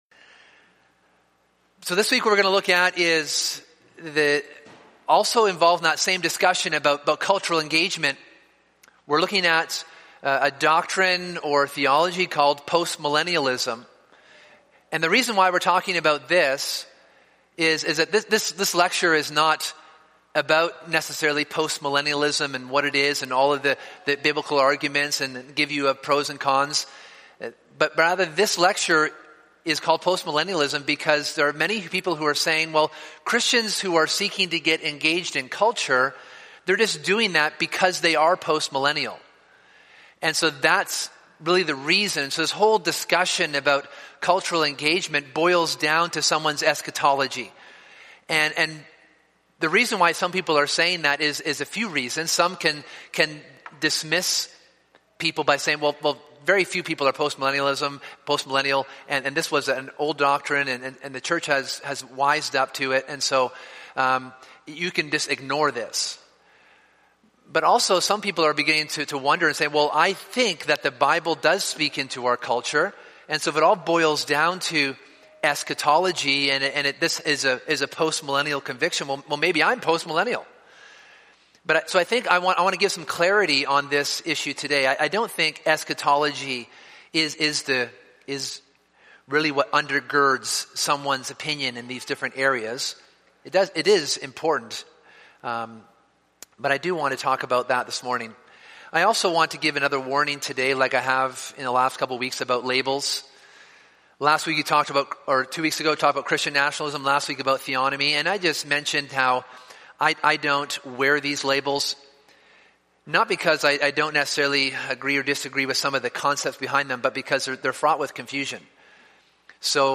This is part three of a seminar series on Christian Nationalism, Theonomy, Postmillenialism, and the Gospel.